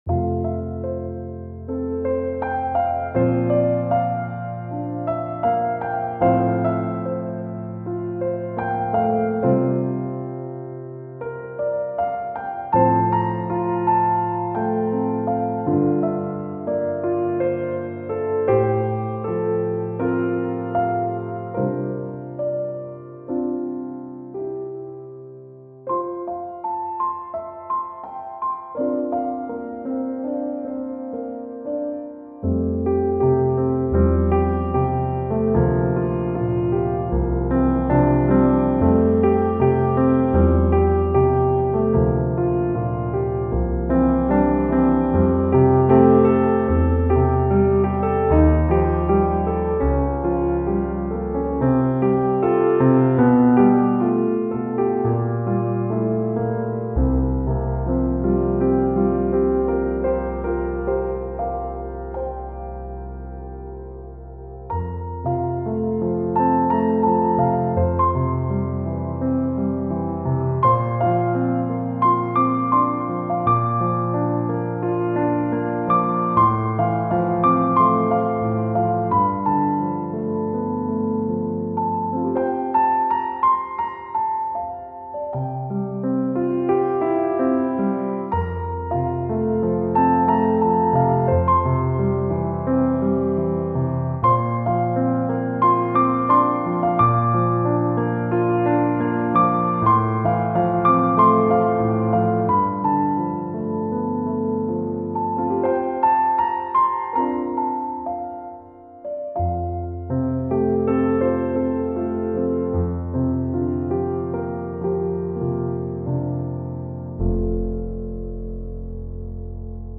Rhythmische Tänze , für Klavier zu 4 Händen .....